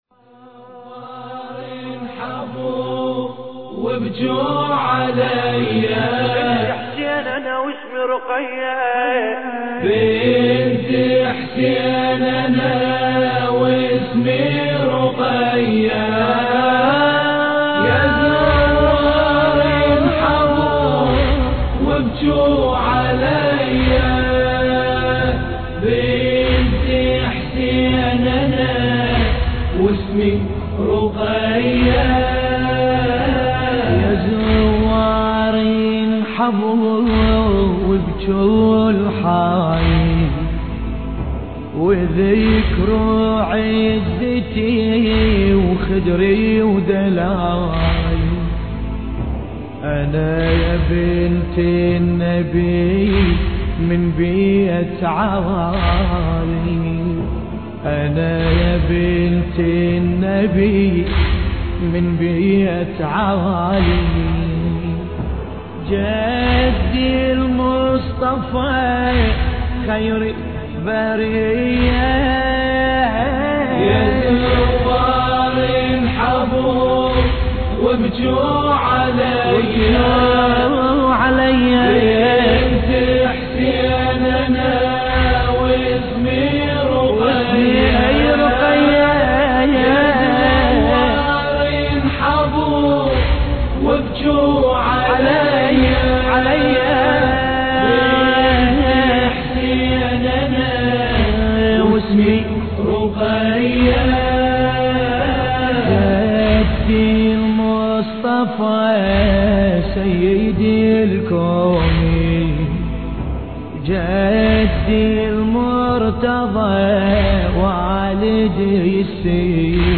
مراثي رقية (س)